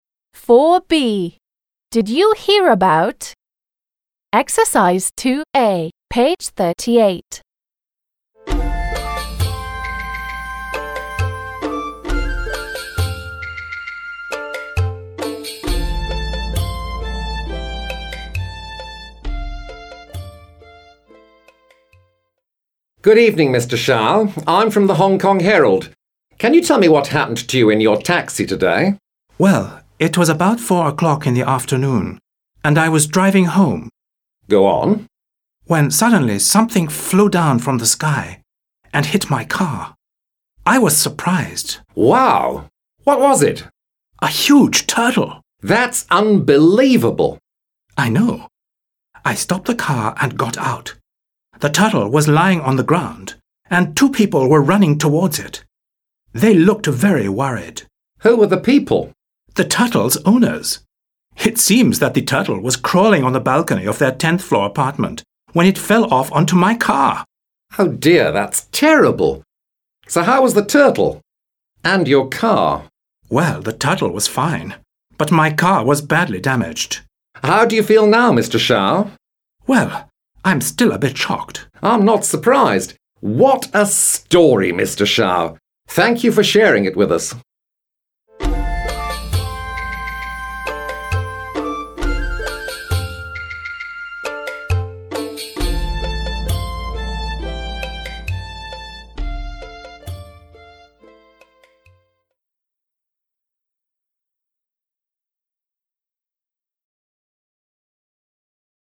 What do you think the interview is about?